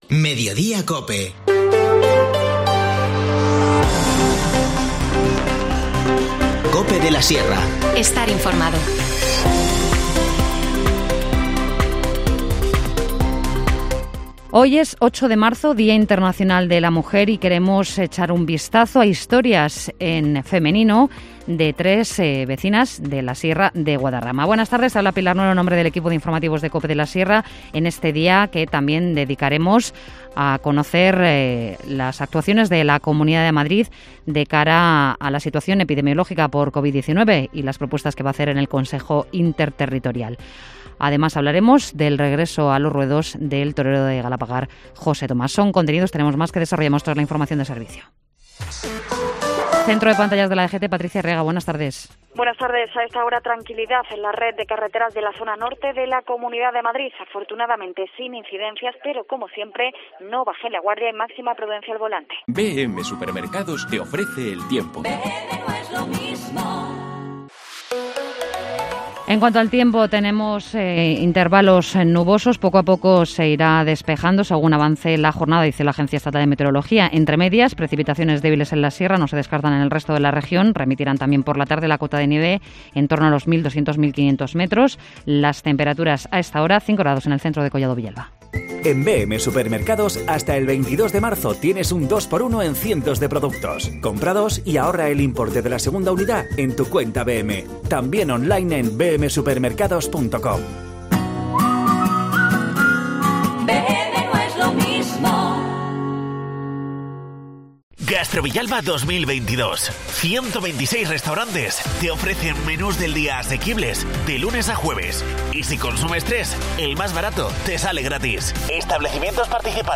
Informativo Mediodía 8 marzo